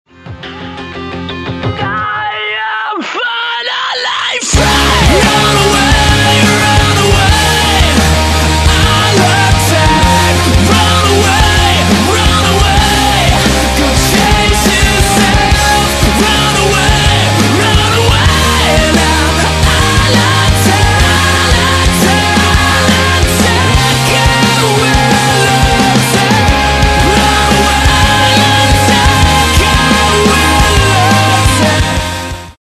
Категория: Рок-музыка